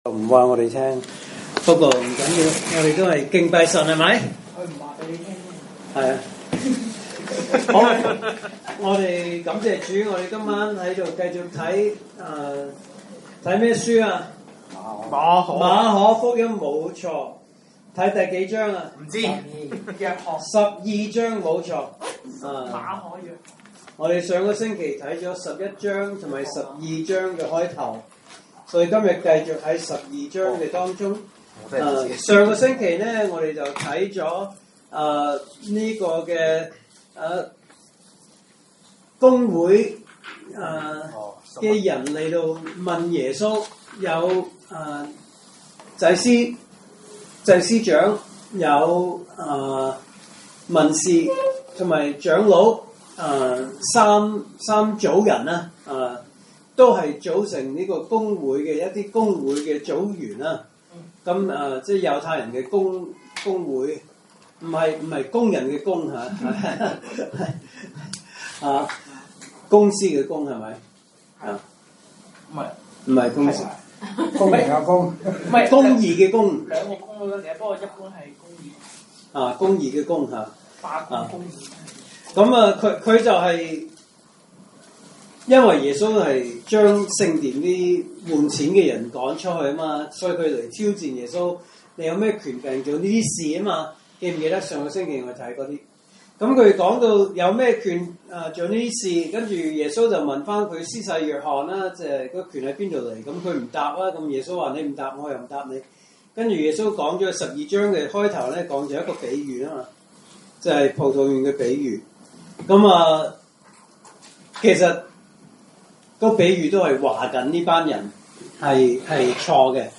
來自講道系列 "查經班：馬可福音"